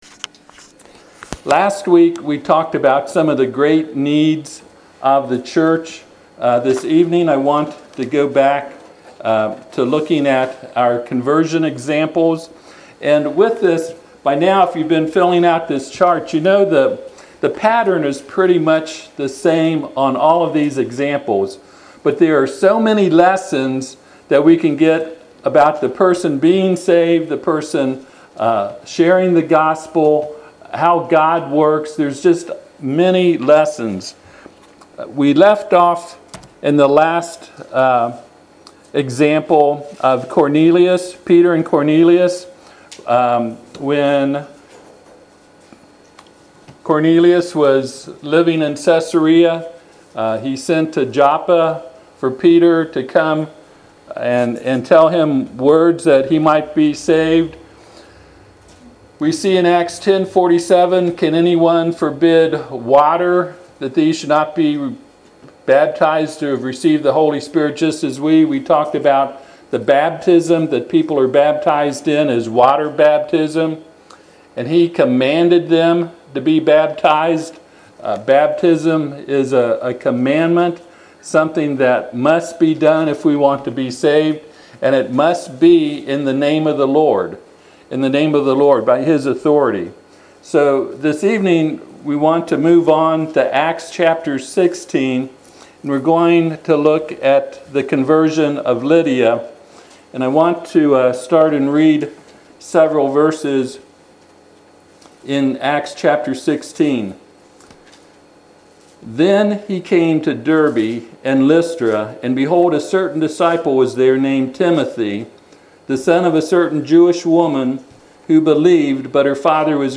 Service Type: Sunday PM Topics: Baptism , Faith , Repentance , Salvation